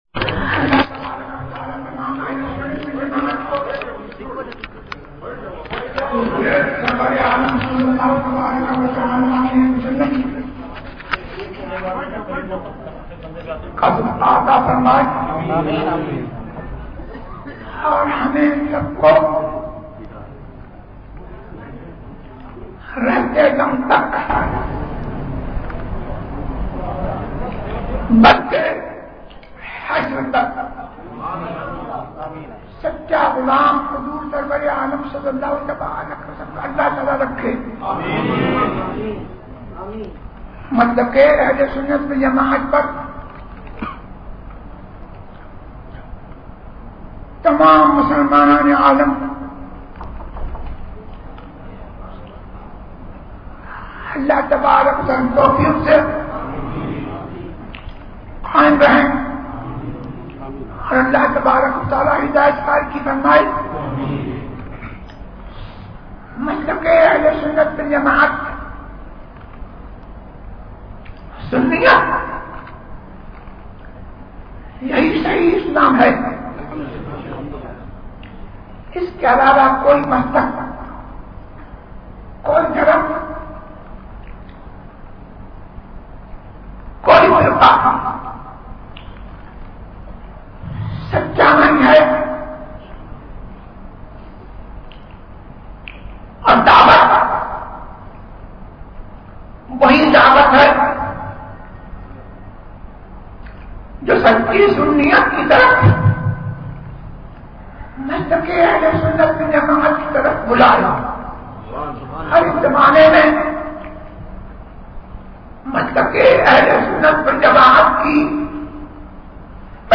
مسلک اعلی حضرت پر قائم رہو ZiaeTaiba Audio میڈیا کی معلومات نام مسلک اعلی حضرت پر قائم رہو موضوع تقاریر آواز تاج الشریعہ مفتی اختر رضا خان ازہری زبان اُردو کل نتائج 1084 قسم آڈیو ڈاؤن لوڈ MP 3 ڈاؤن لوڈ MP 4 متعلقہ تجویزوآراء